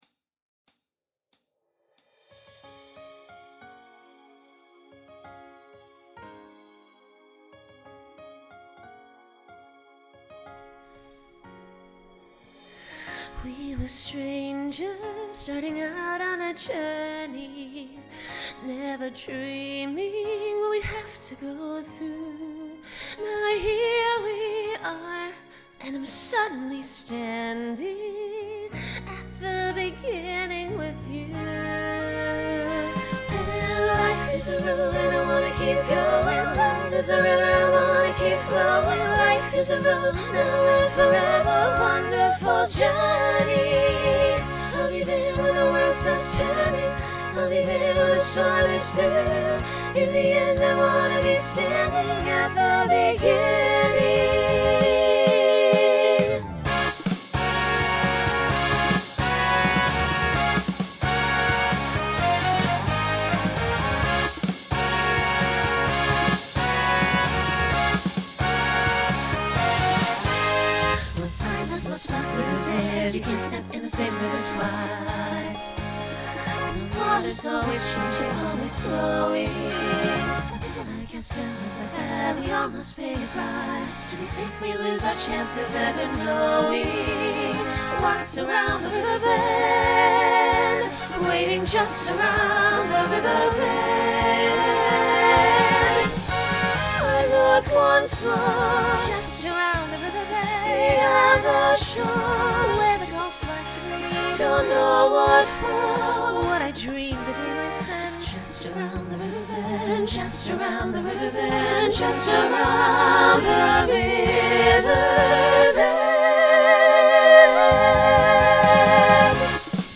Decade 1990s Genre Broadway/Film
Voicing SAB